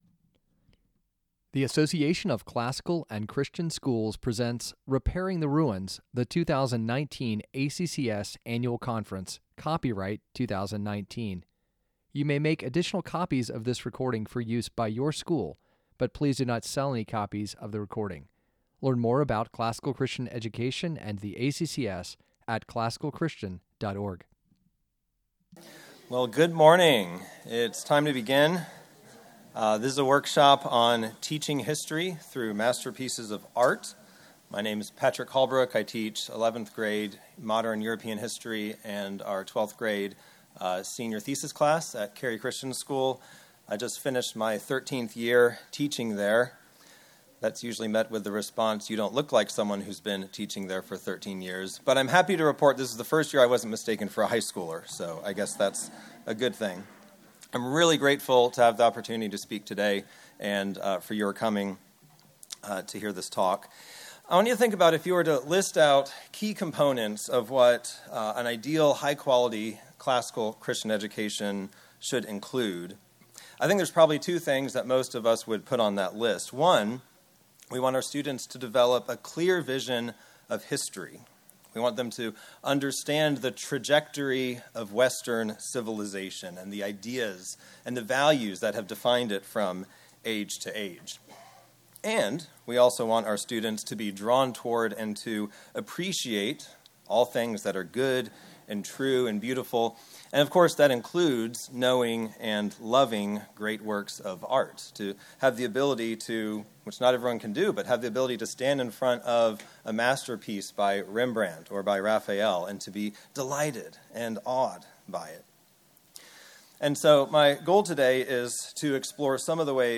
2019 Workshop Talk | 59:12 | 7-12, History